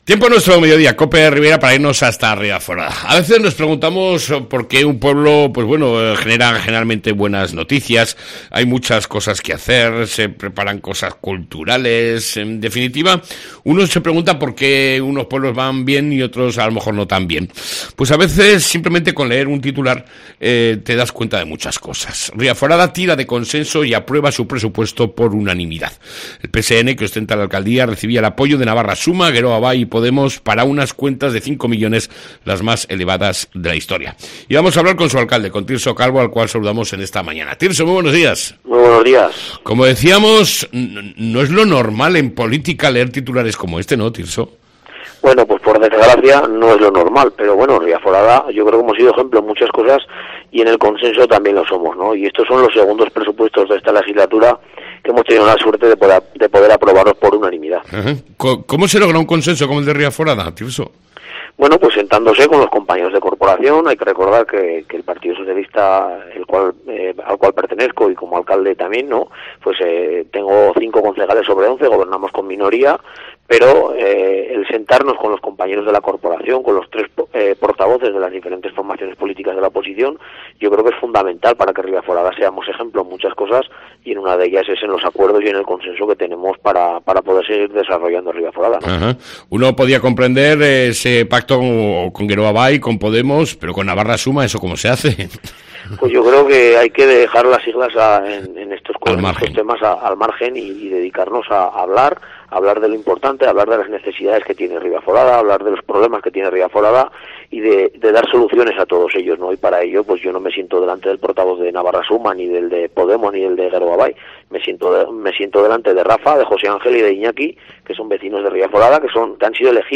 ENTREVISTA CON EL ALCALDE DE RIBAFORADA, TIRSO CALVO